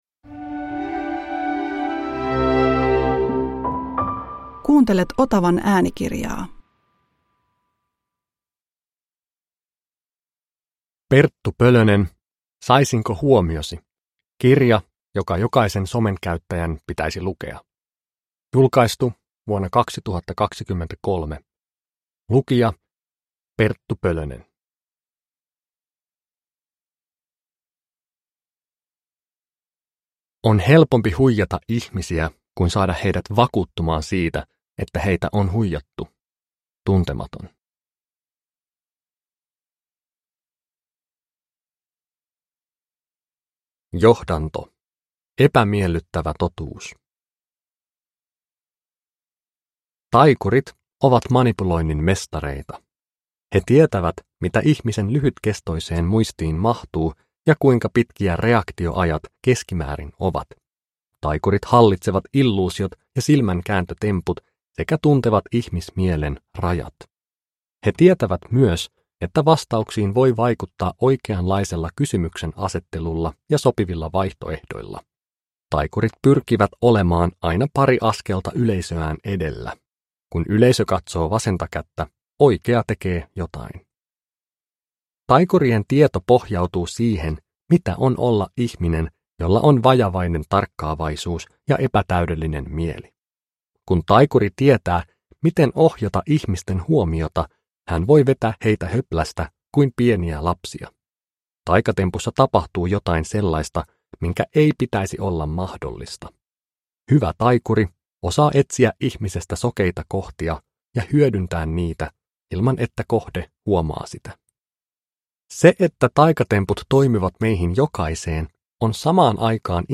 Saisinko huomiosi? – Ljudbok